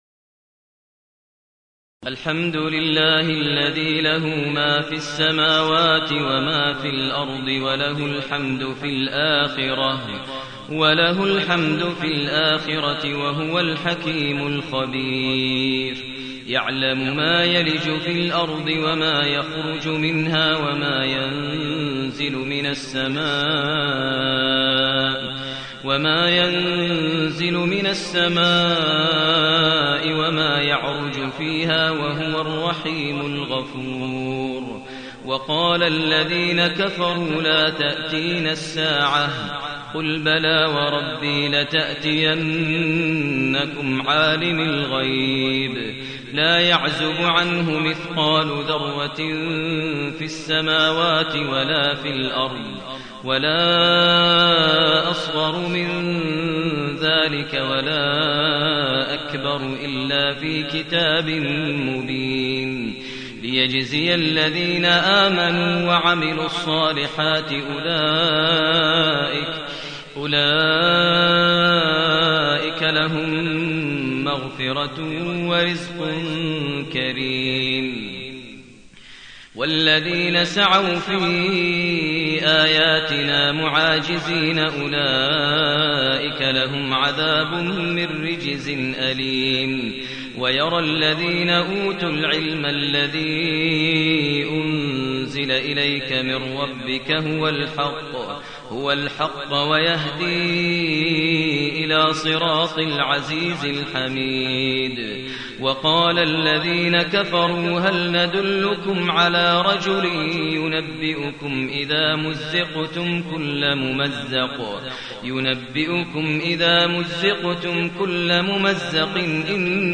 المكان: المسجد النبوي الشيخ: فضيلة الشيخ ماهر المعيقلي فضيلة الشيخ ماهر المعيقلي سبأ